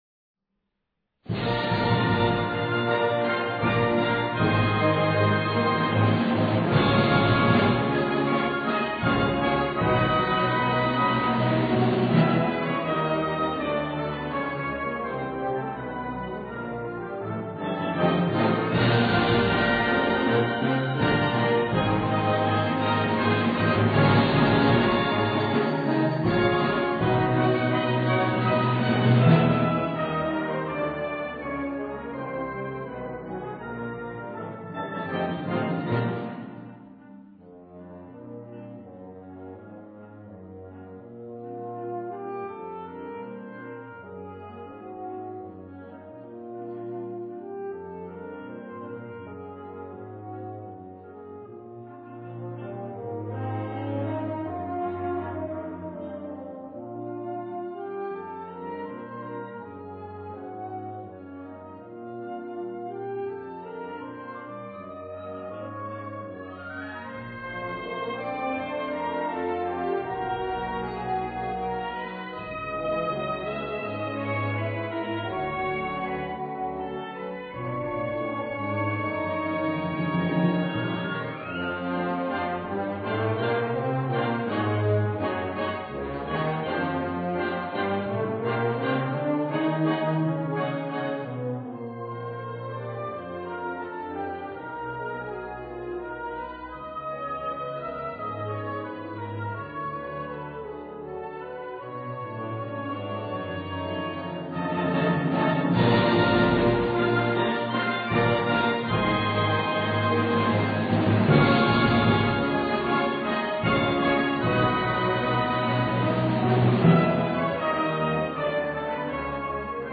Suite per banda